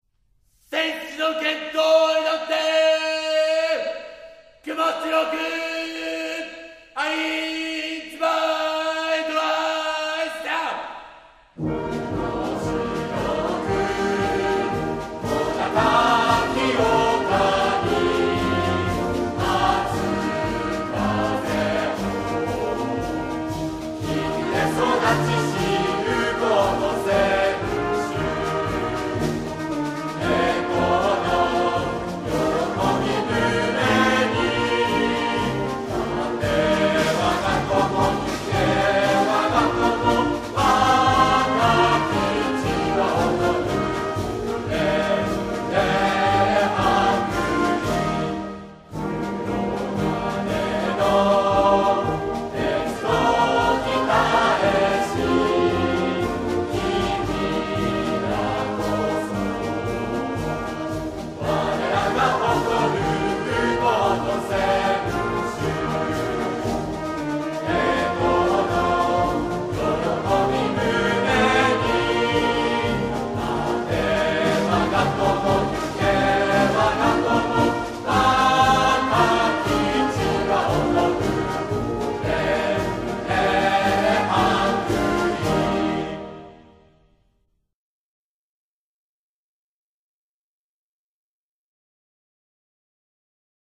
羽咋高校応援歌